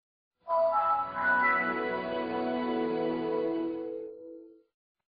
На этой странице собраны классические звуки Windows Vista — системные уведомления, мелодии запуска и завершения работы, а также другие аудиоэлементы ОС.
Звук приветствия Windows 7